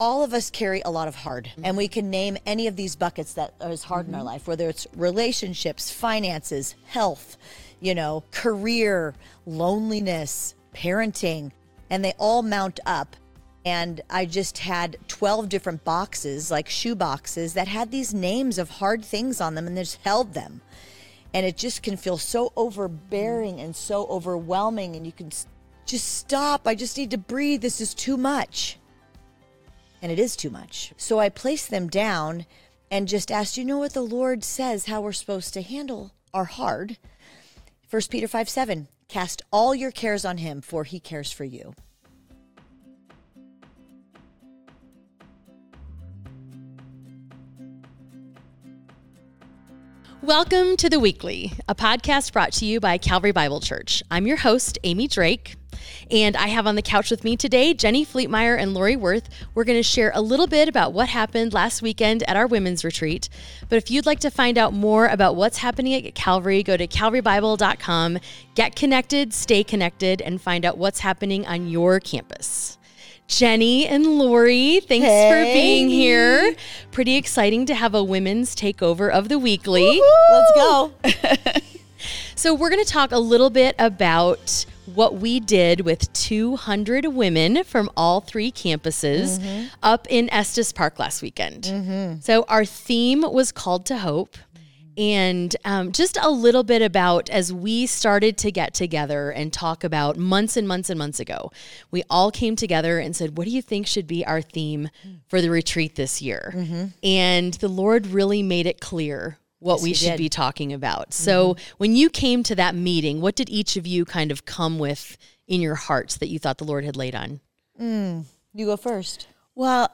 Whether you're in a hard season or just need a reminder of where to anchor your heart, this conversation is honest, warm, and full of hope.